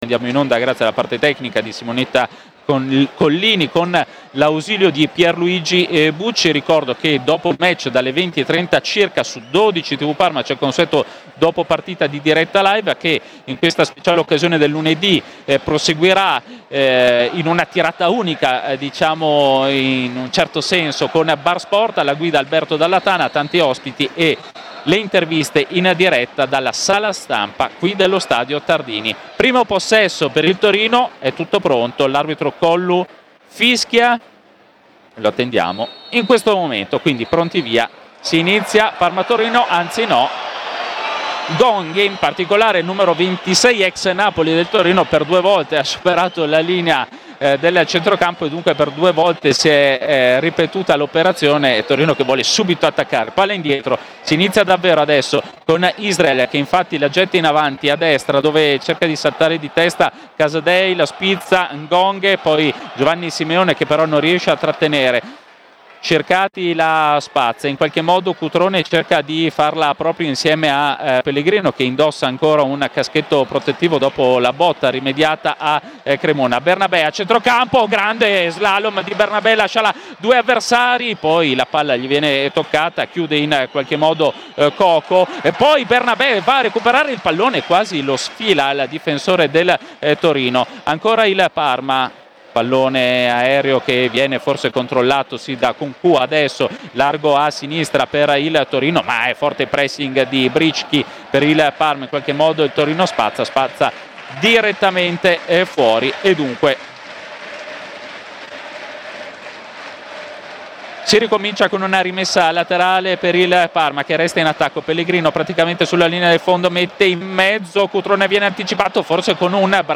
Radiocronache Parma Calcio Parma - Torino 1* tempo - 29 settembre 2025 Sep 29 2025 | 00:48:58 1x 00:00 / 00:48:58 Subscribe Share RSS Feed Share Link Embed